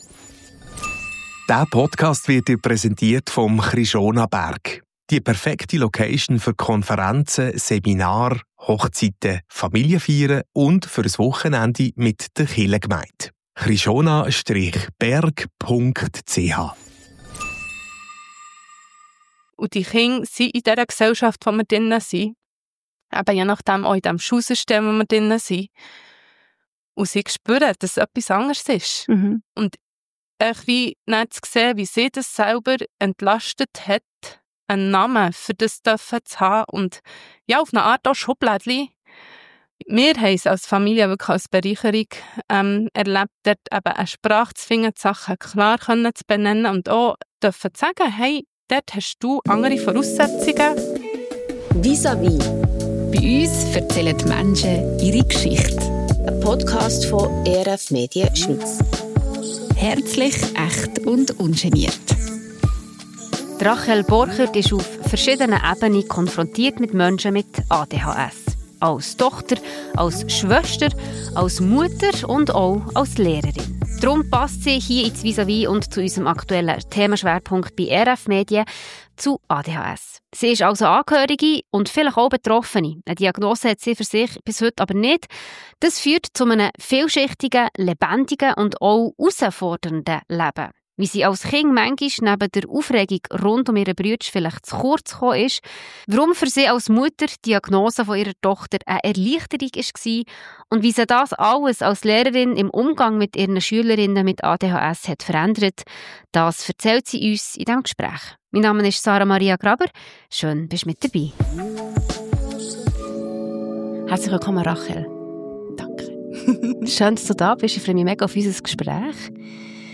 Im Gespräch erzählt sie von den grössten Herausforderungen und den grössten Hilfen im Leben mit ADHS, von Stigmen und Schubladen im Kopf, von tragenden Beziehungen und ihrem starken Glauben an Gott.